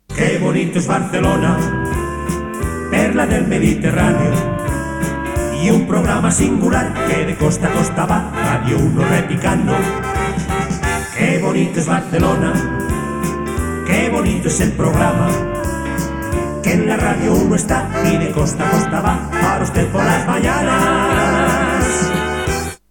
Indicatiu cantat del programa